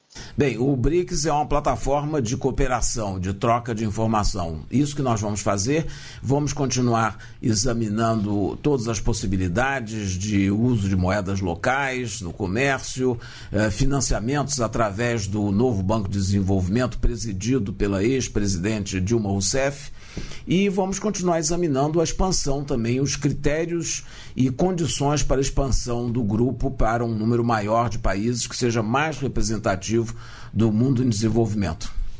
Em entrevista a radialistas de vários estados no programa Bom Dia, Ministro desta quarta-feira (21), o ministro da Pesca e Aquicultura, André de Paula, destacou temas como exportação, seguro defeso e mudanças climática.